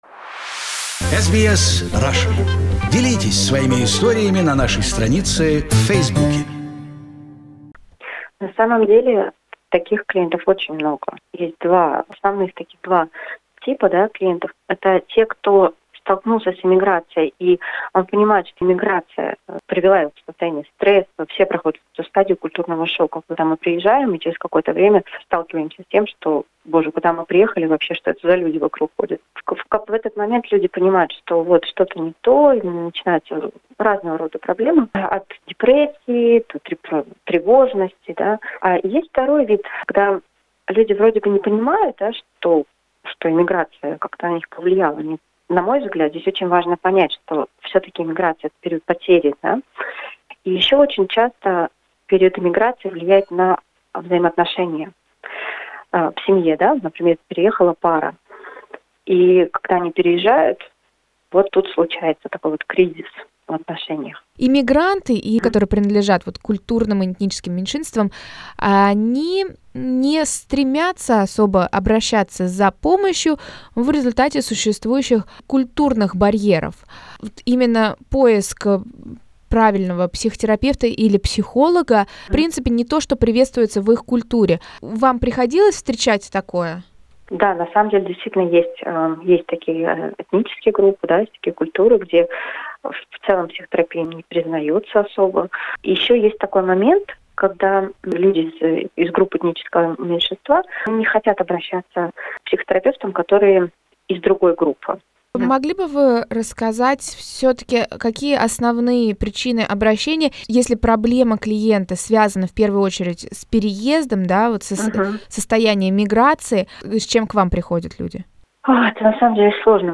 We talk with the psychotherapist, counselor